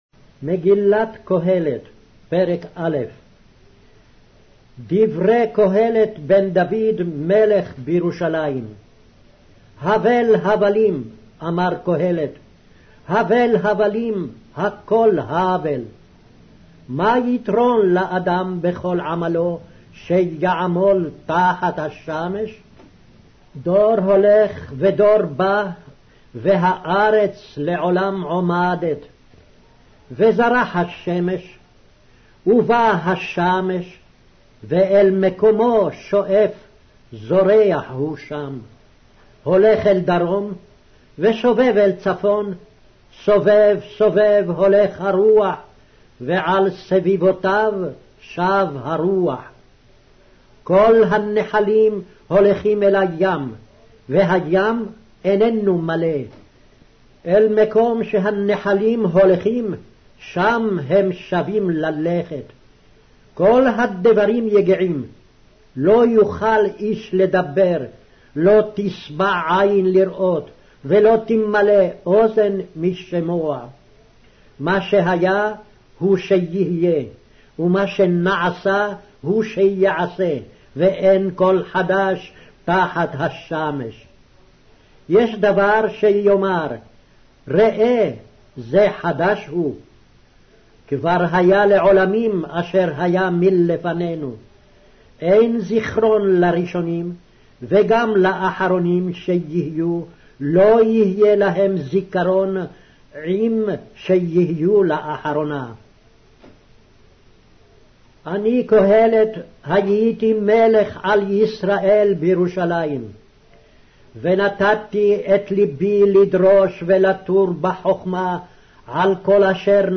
Hebrew Audio Bible - Ecclesiastes 9 in Ervkn bible version